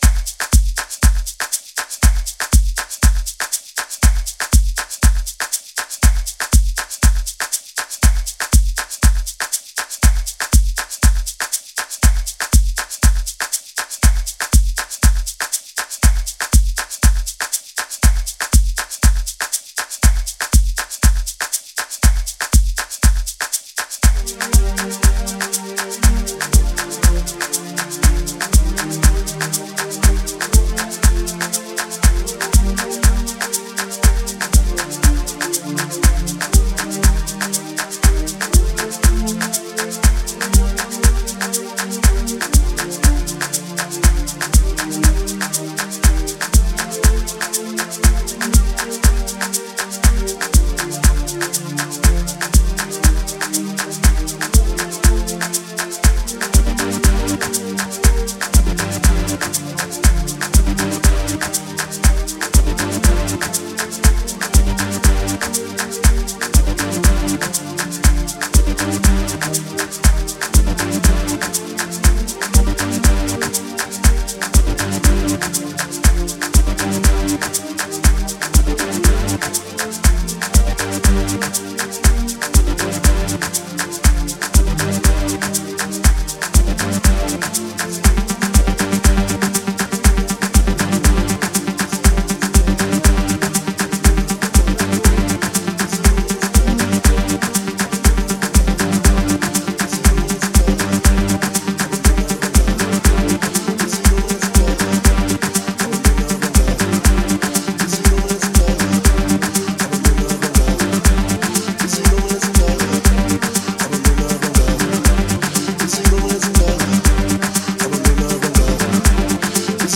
08:48 Genre : Local House Size